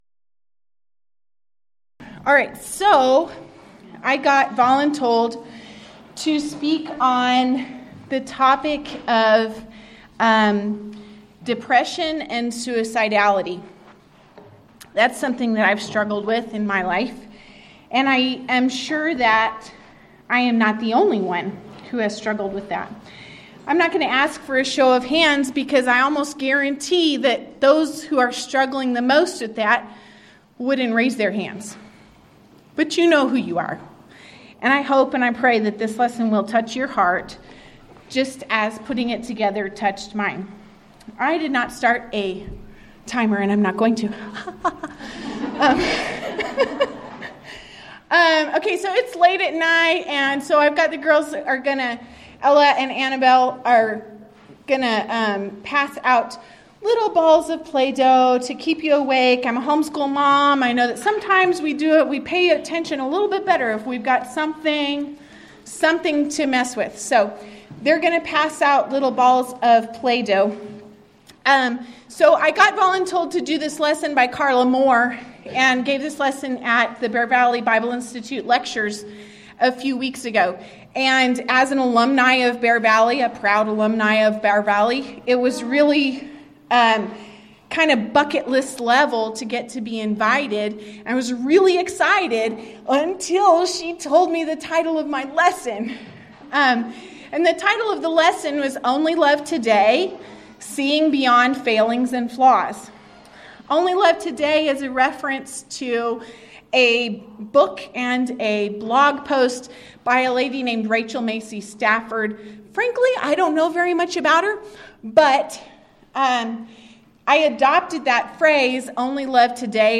Title: Thursday Evening Devotional
Event: 10th Annual Texas Ladies in Christ Retreat Theme/Title: Righteousness Overviewed